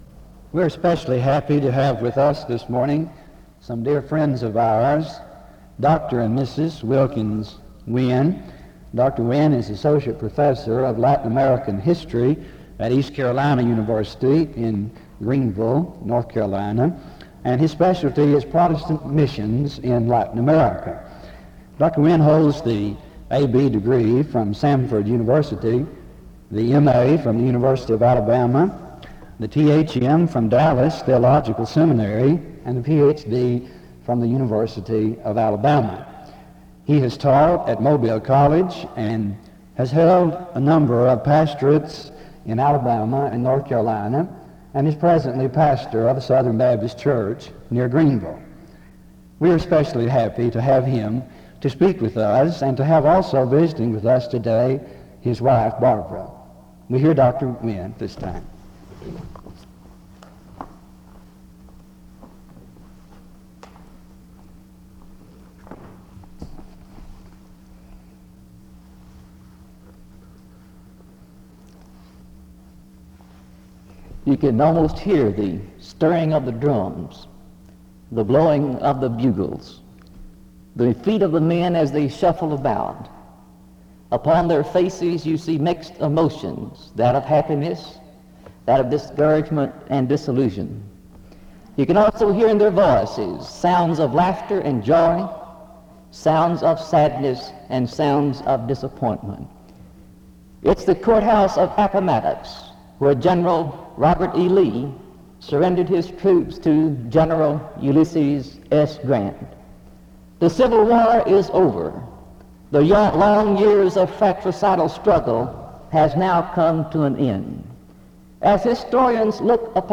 The service opens with an introduction to the speaker from 0:00-0:58.